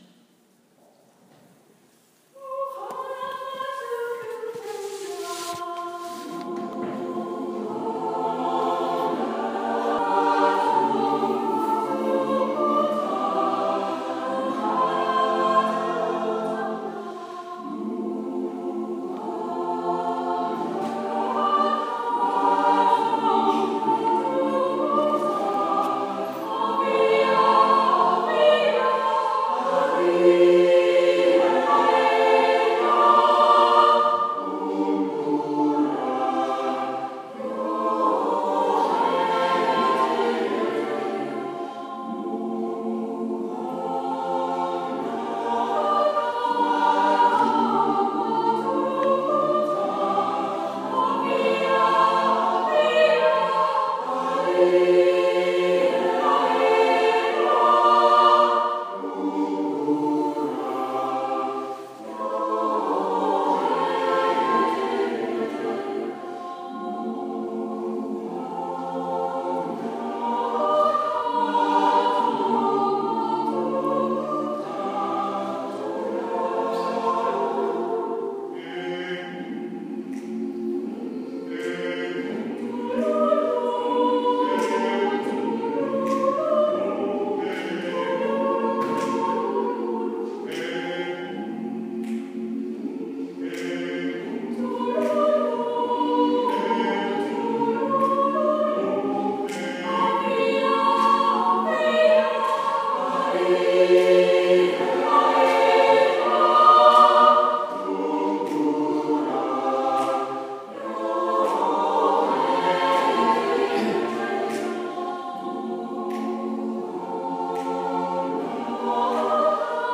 Kirchenchor Sigharting
Zahlreiche Frauen und auch einige Männer verschönern mit ihrem Gesang nicht nur den wöchentlichen Sonntagsgottesdienst, sondern auch viele kirchliche Feste und Feiern.